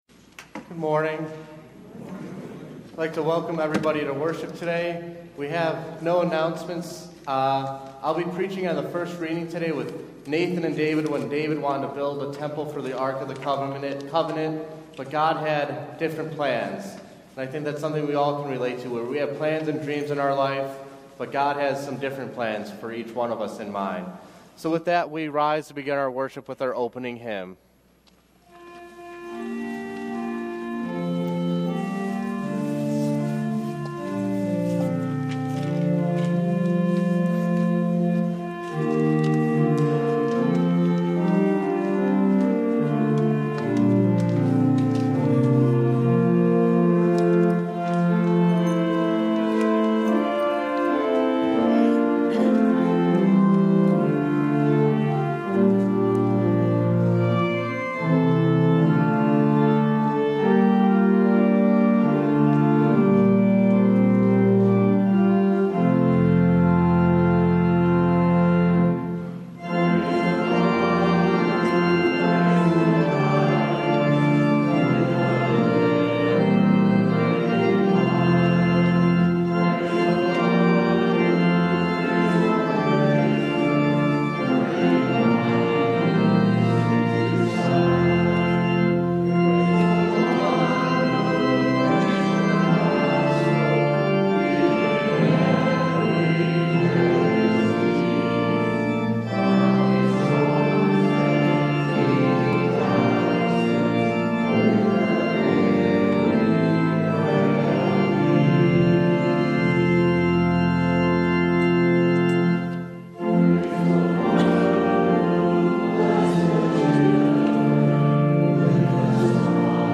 Feb 25 / Divine – God’s Will and My Dreams – Lutheran Worship audio